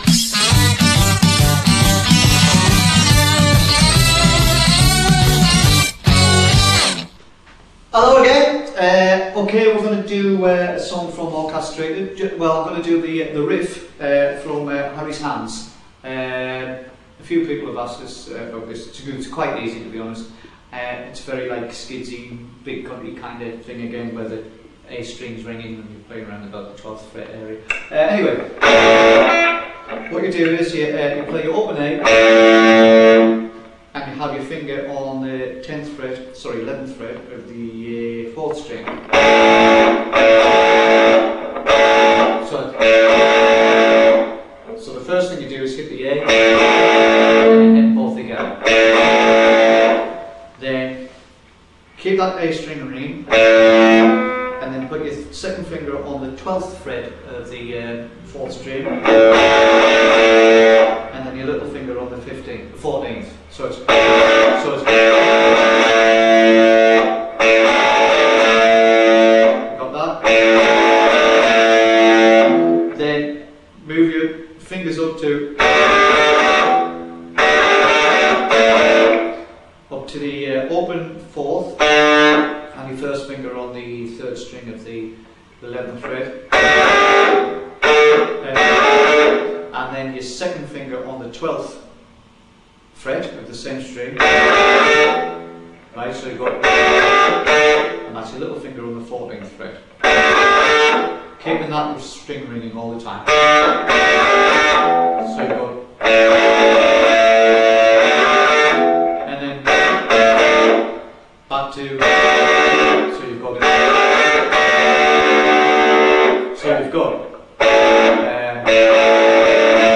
Download 6 min 20 sec (19.73MB)   Guitar Lesson 8 - Harry's Hands Riff